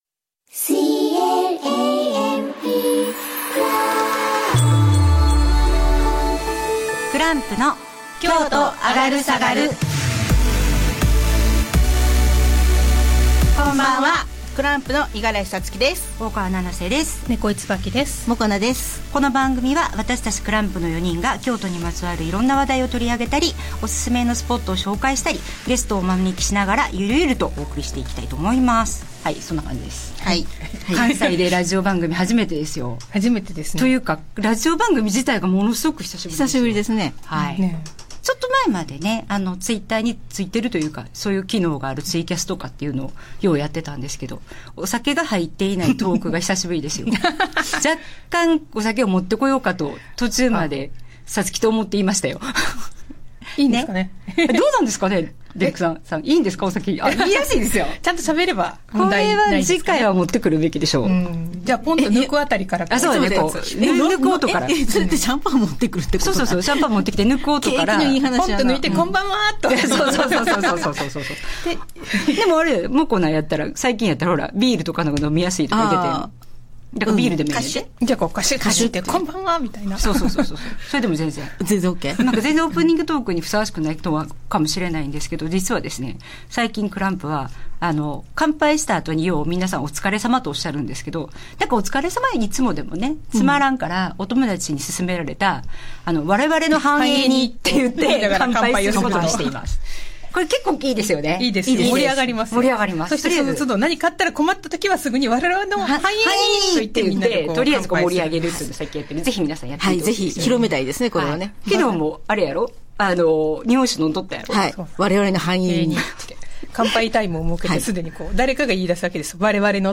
CLAMP’s weekly radio show on KBS Kyoto radio station launched today in Japan.